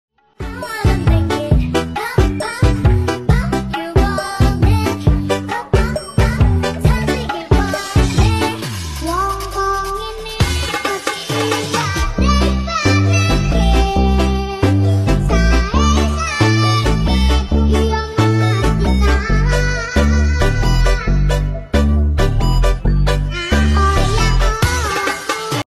#2stroke